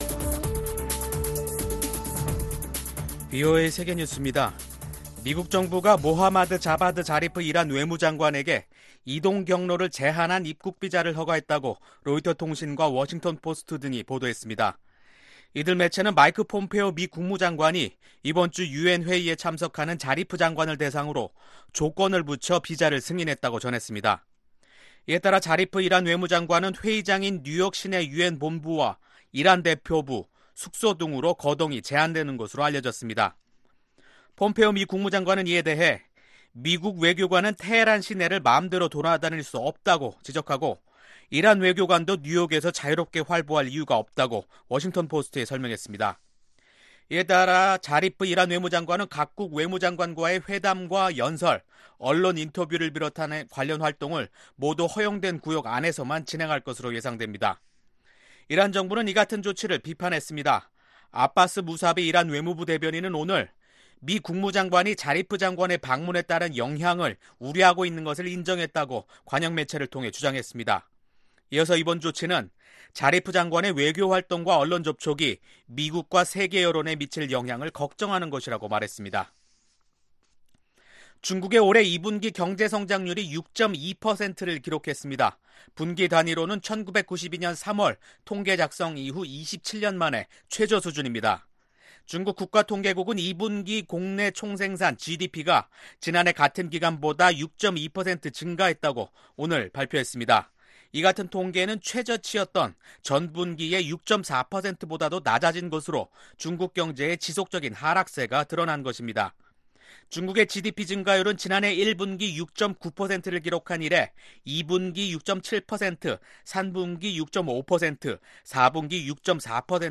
VOA 한국어 간판 뉴스 프로그램 '뉴스 투데이', 2019년 7월 15일 2부 방송입니다. 마이크 폼페오 미국 국무장관은 북한의 완전한 비핵화를 달성한다면 이는 역사적인 업적이 될 것이라고 말했습니다. 미 하원이 통과한 2020회계연도 국방수권법안에는 대북 제재 강화와 북한 비핵화, 한국전쟁 종전을 위한 외교 추구 등의 조항이 포함됐습니다.